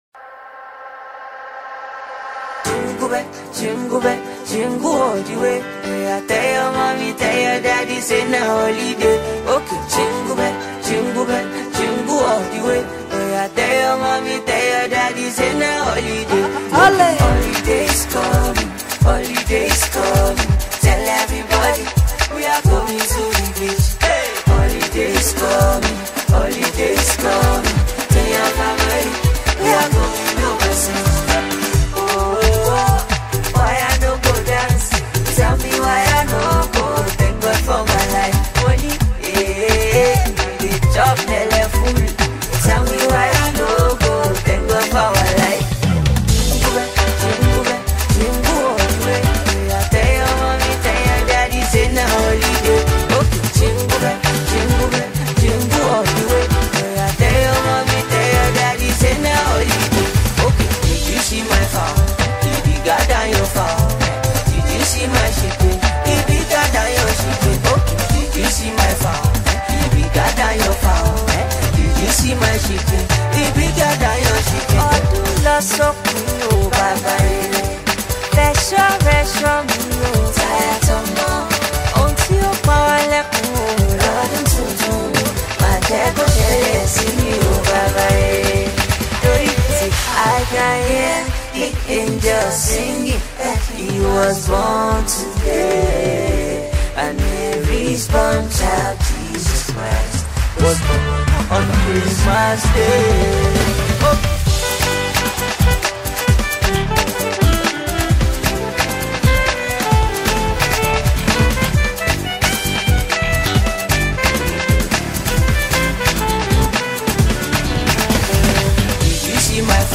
A Christmas song